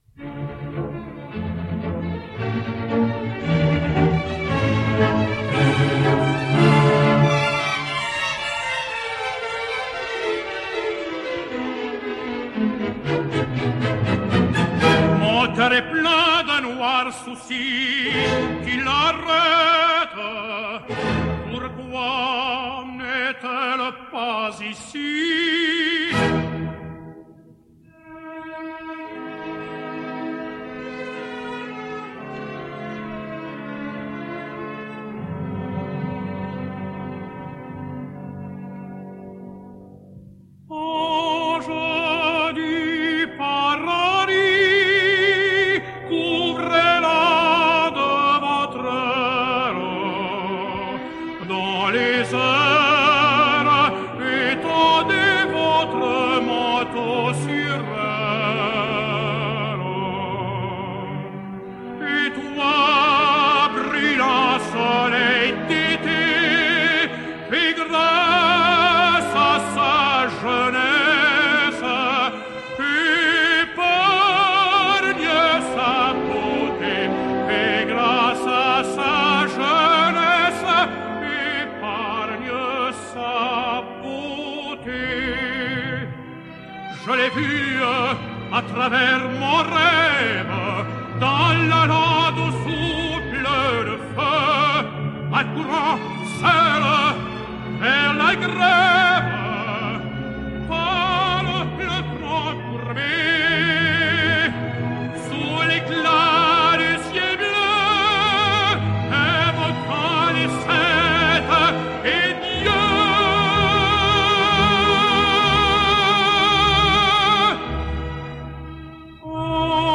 French Tenor
Angels of Paradise / Mirelle / 1972 – Alain Vanzo